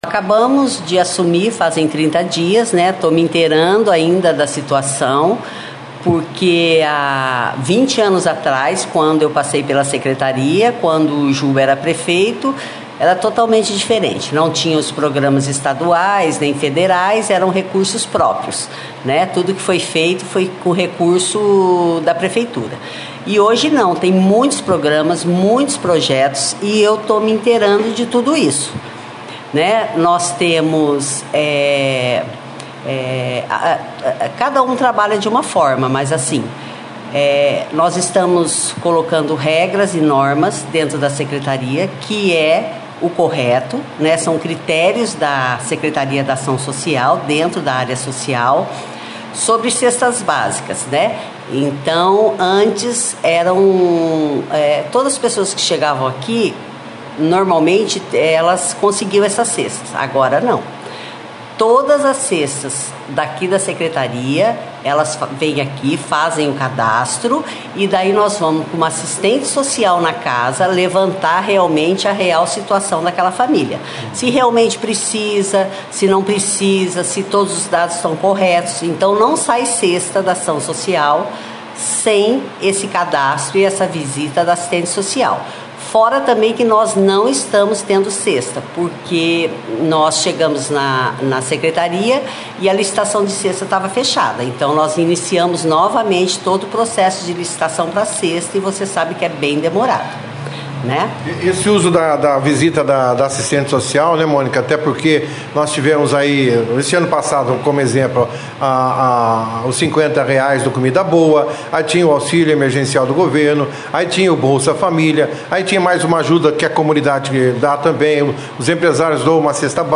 Secretária e assistente social falam dos novos critérios no atendimento social de Bandeirantes